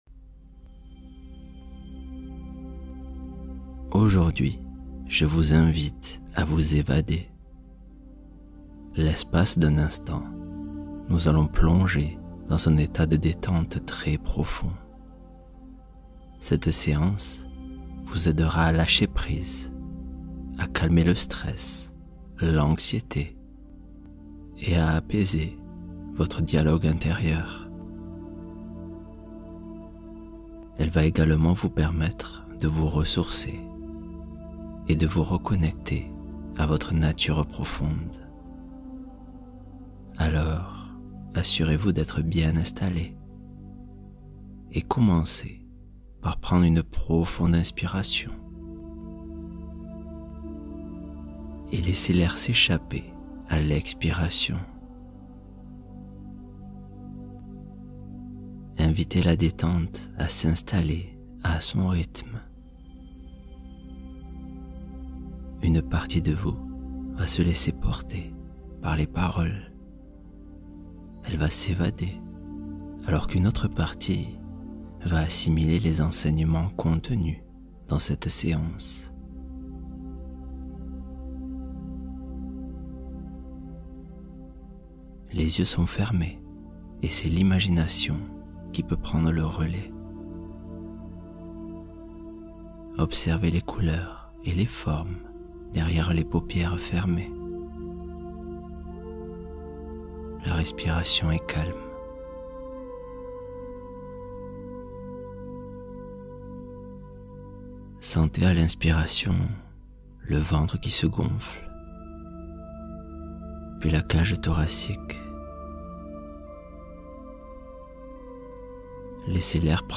Histoire de lâcher-prise — Méditation narrative du soir